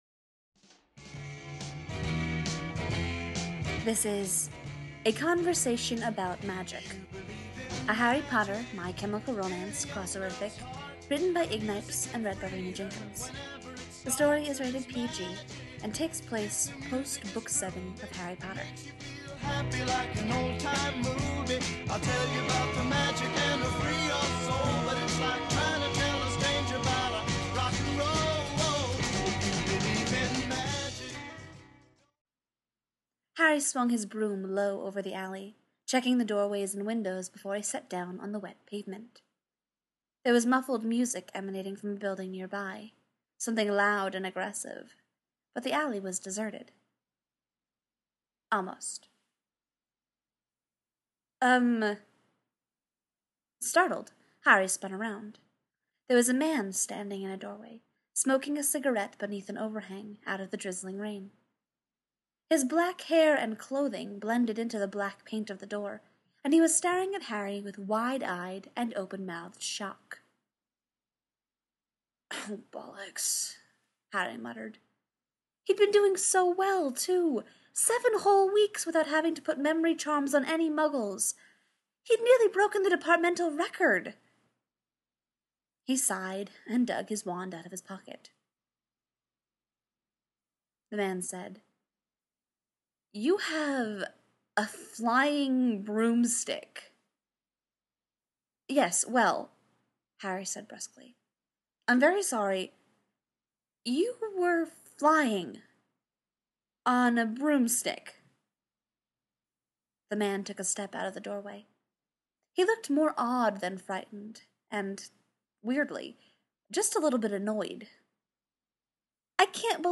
b-i-n-g-ohhhhh, other people's fanfic, fandom: harry potter, podfic, i made coverart!, no more megaupload, fandom: scruffy bandboys love each other